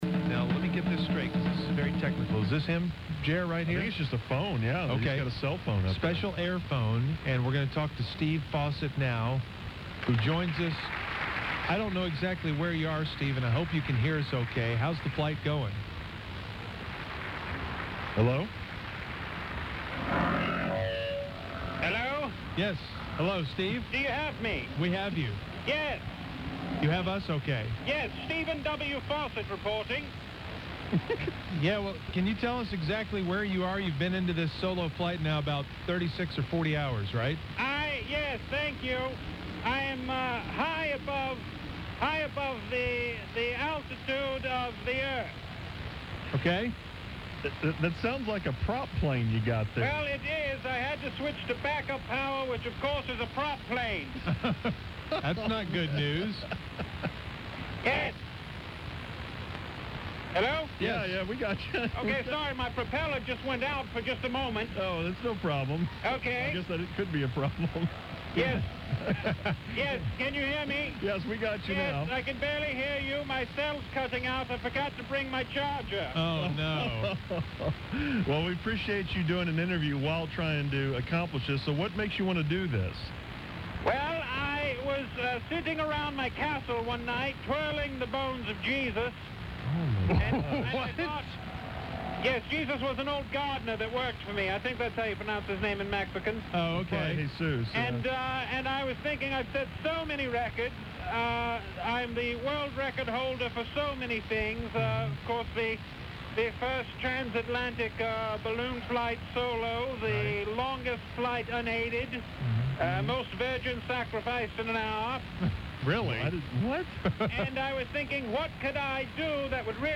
The Musers interview Steve Fossett while he solo flies the globe non-stop